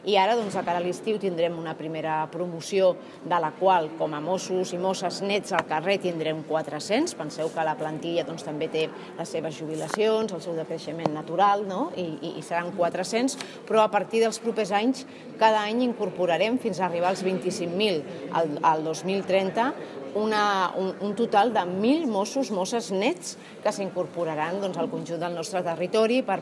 La consellera d’Interior, Núria Parlón, ha qualificat de tímida però important la davallada de la situació delictiva a Tortosa. Per això ha dit que es continuarà treballant per reforçar la presència policial al carrer i ha avançat que a partir del 2026 s’incorporaran al cos de Mossos,  un miler de nous agents anualment per assolir una plantilla de 25.000 el 2030.